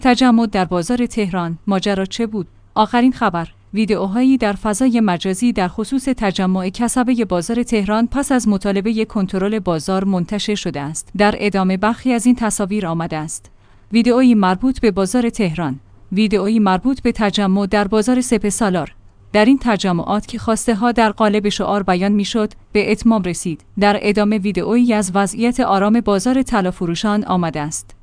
ویدئویی مربوط به بازار تهران بازار توضیح ویدیو ویدئویی مربوط به تجمع در بازار سپه سالار توضیح ویدیو در این تجمعات که خواسته‌ها در قالب شعار بیان می‌شد، به